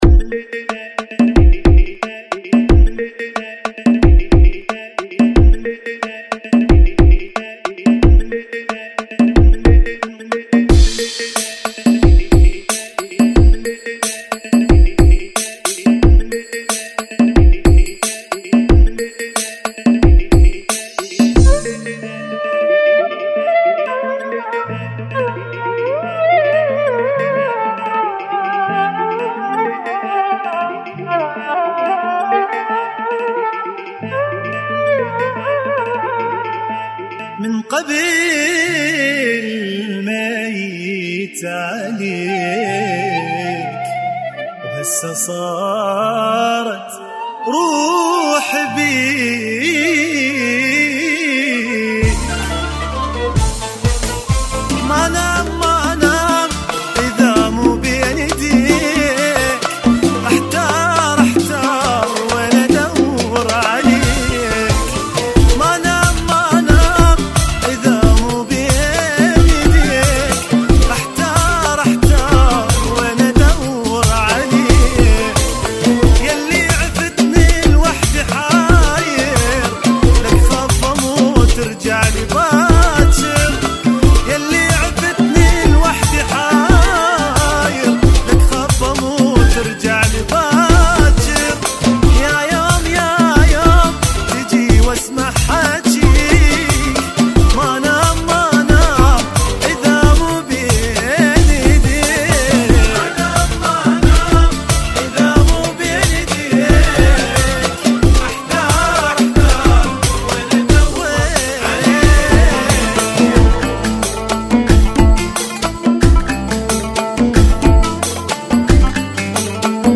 Funky [ 90 Bpm ]